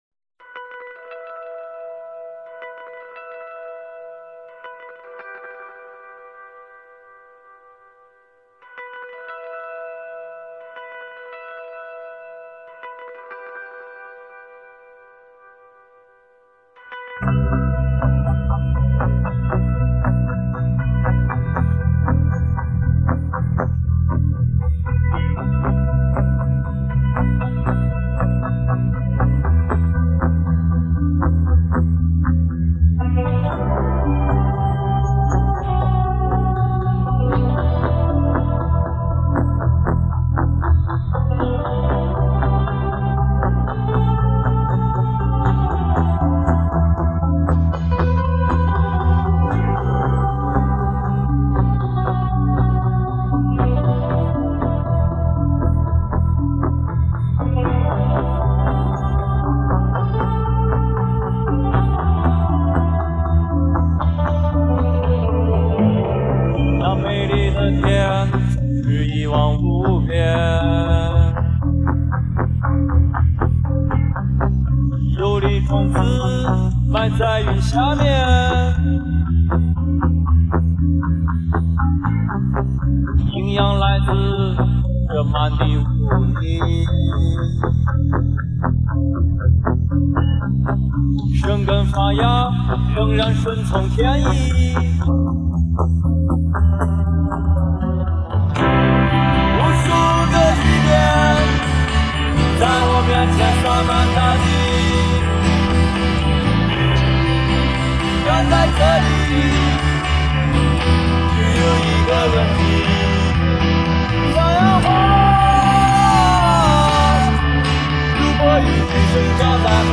无鼓伴奏